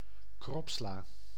Ääntäminen
France: IPA: [la lɛ.ty]